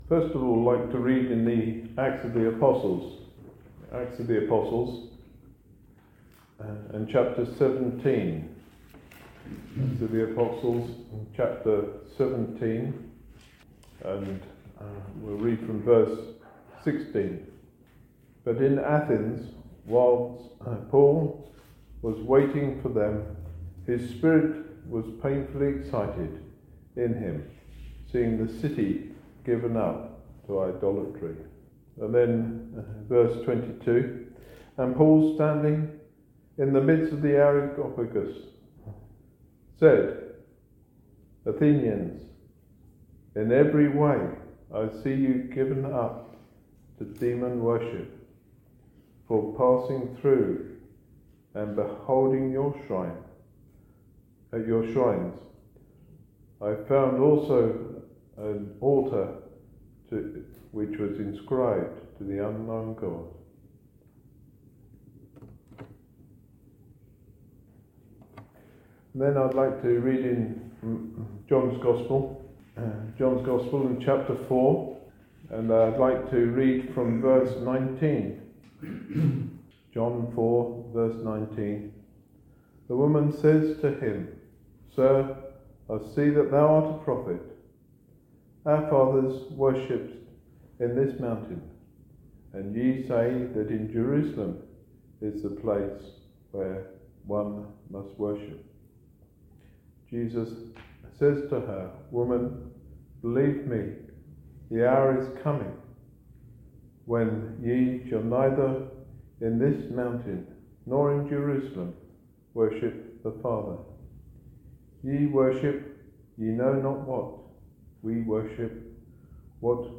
God isn’t looking for religion—He’s seeking true worshipers. Through the Gospel, He is calling us to Himself, drawing us to Jesus who went to the cross to bring salvation for mankind. This preaching invites you to respond to that call and worship God in spirit and in truth.